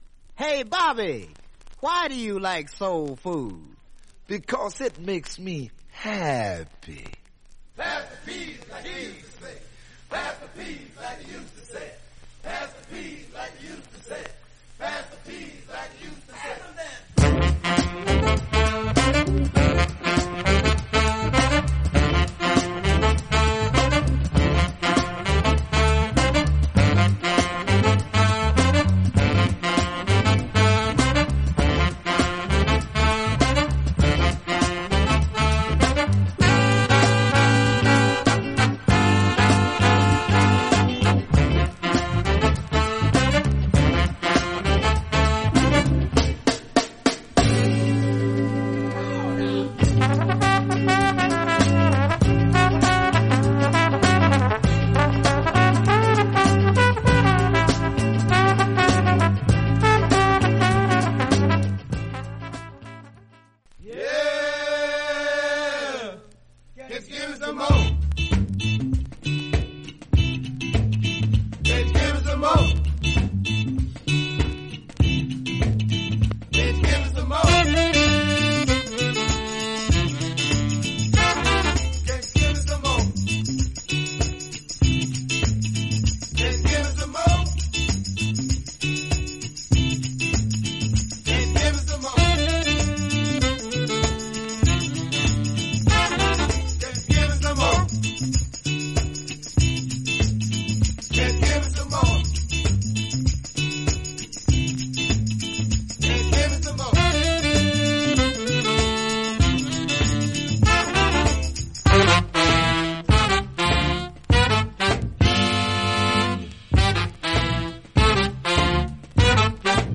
盤面薄いスレが少しありますが音に影響ありません。
実際のレコードからのサンプル↓